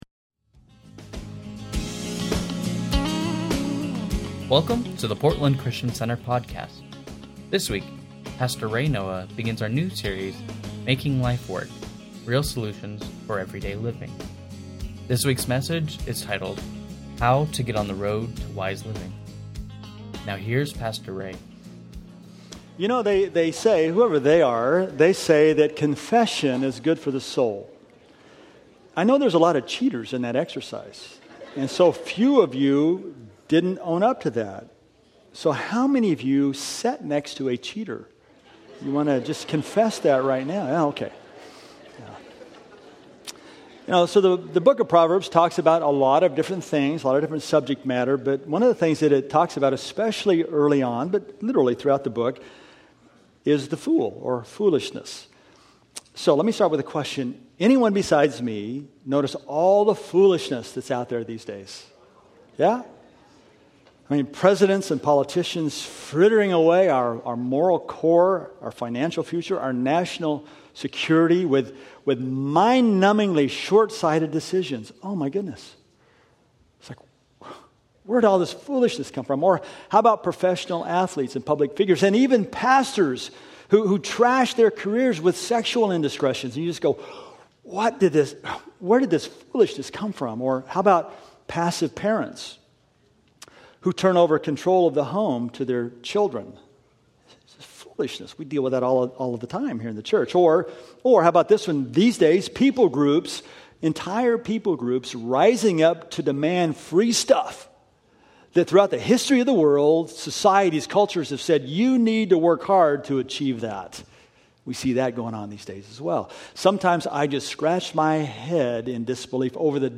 Sunday Messages from Portland Christian Center How To Get On The Road To Wise Living Feb 07 2016 | 00:39:36 1x 00:00 / 00:39:36 Subscribe Share Spotify RSS Feed Share Link Embed